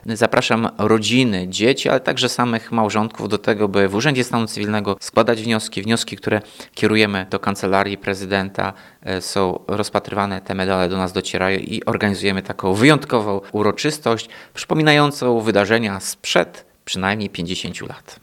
Prezydent zachęca również wszystkie pary z Ełku o składanie wniosków o takie odznaczenie.